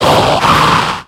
Cri de Cacturne dans Pokémon X et Y.